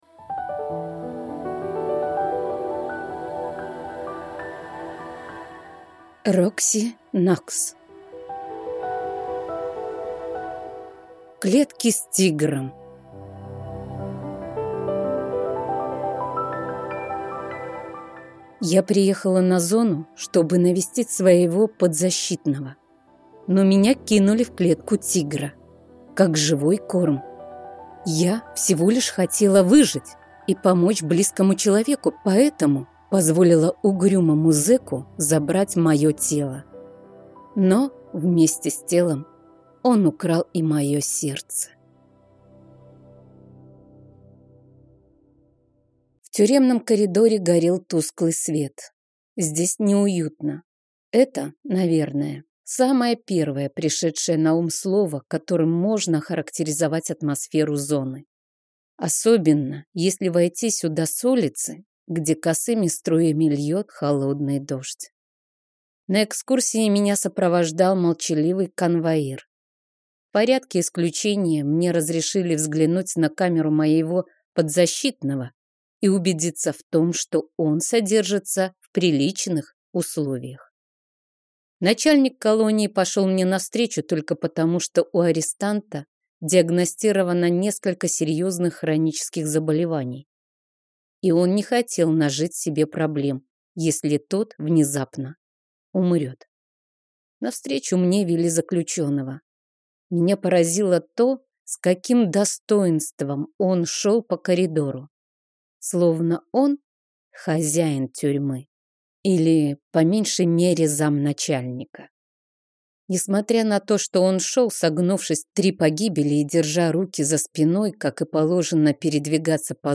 Аудиокнига «ООО «Иной мир». Филиал ада».